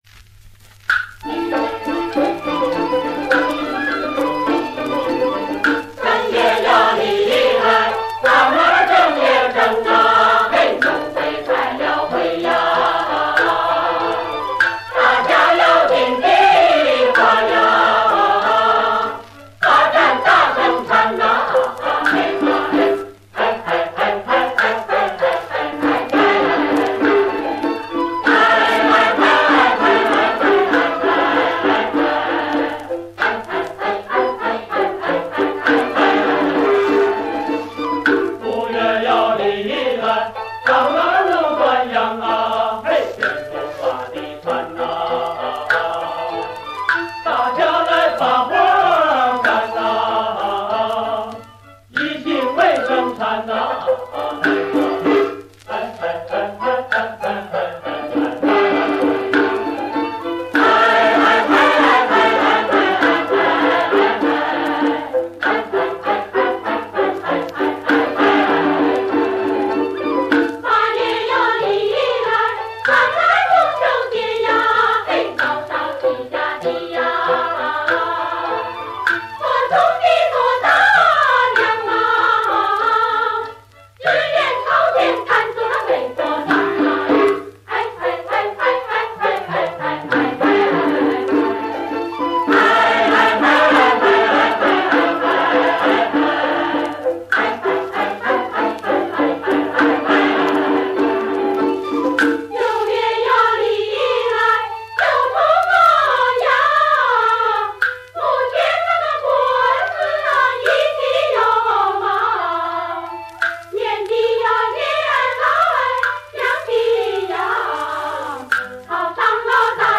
东北民歌